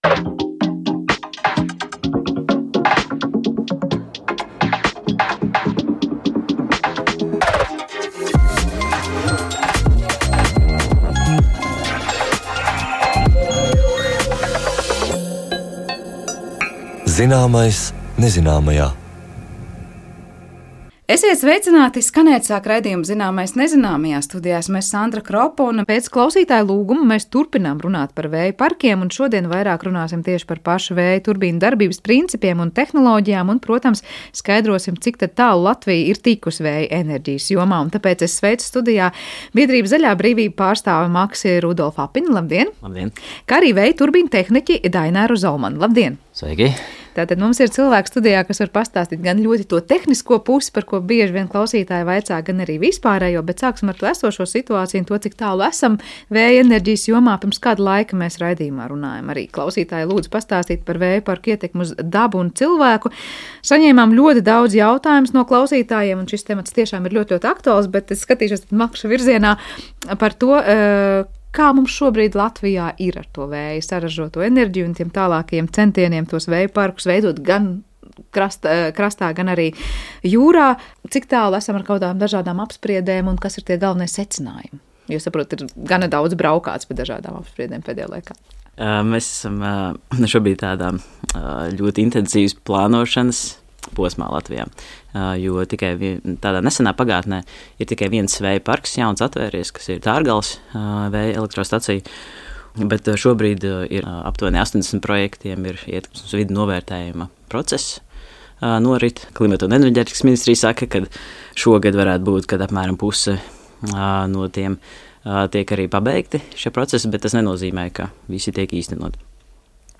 Īpaša uzmanība veltīta latviešu zinātnieku sasniegumiem pasaulē un Latvijā. Katrā raidījumā kopā ar pētniekiem iztirzājam „galveno tematu”, uz sadarbību aicinot arī savus klausītājus, vai kā citādi uzklausot cilvēku viedokļus un jautājumus.